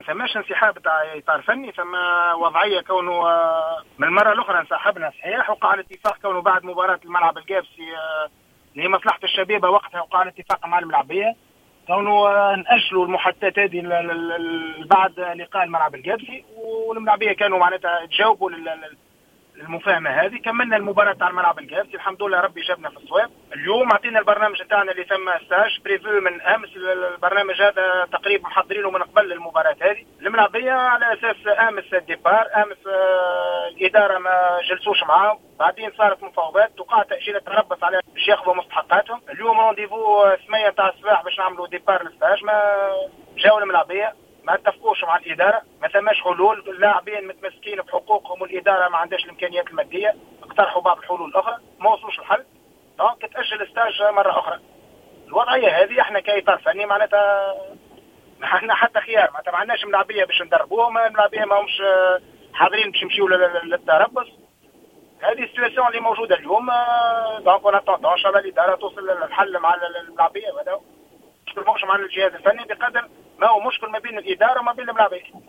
أكد المدرب جلال القادري في تصريح لجوهرة أف أم أن الظروف التي تمر بها الشبيبة غير ملائمة للعمل في ظل غياب الهيئة المديرة عن المشهد و تعدد إضرابات اللاعبين.